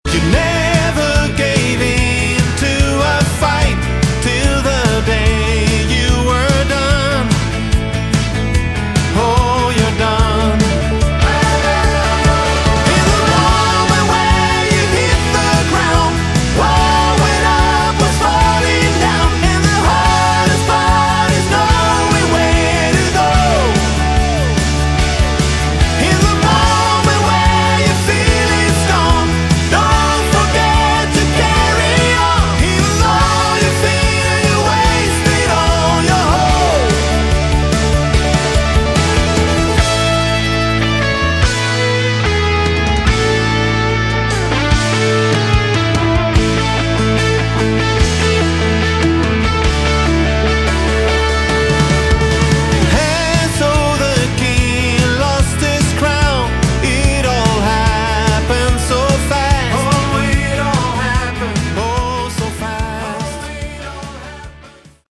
Category: AOR / Melodic Rock
lead vocals, guitars
keyboards
bass
drums